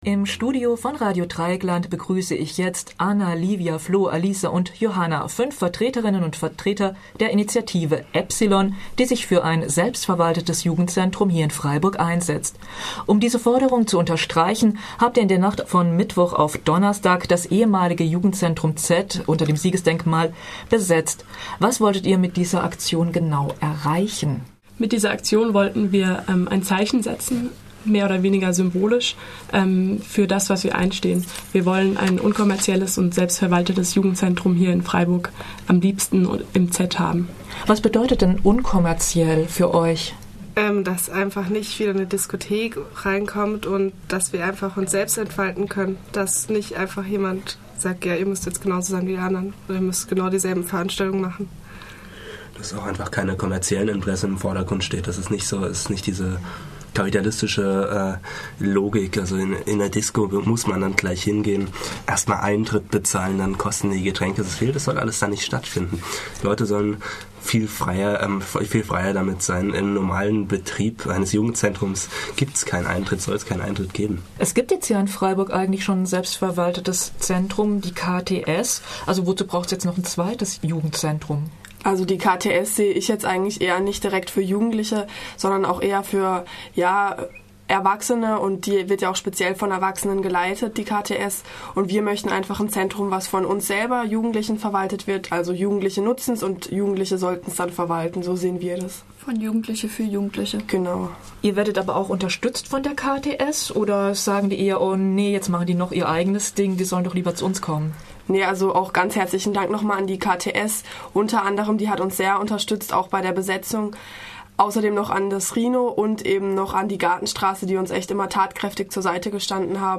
Studiogespräch